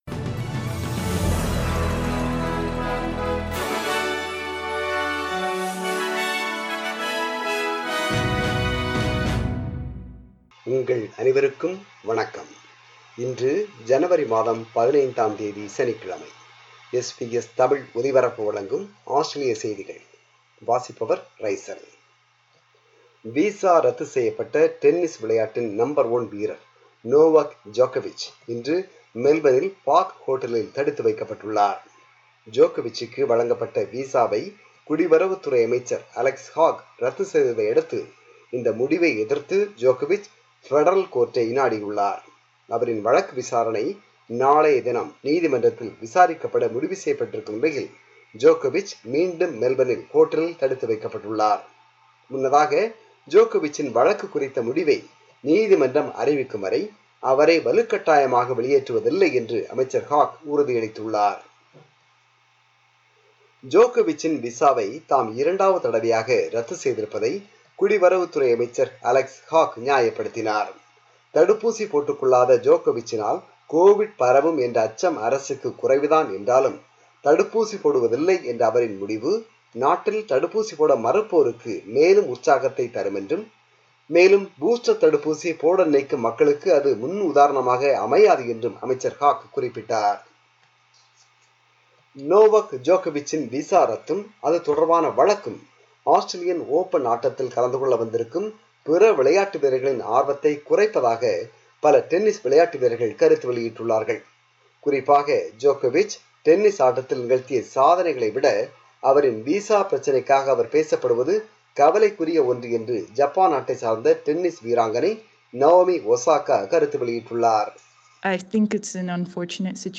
Australian News: 15 January 2022 – Saturday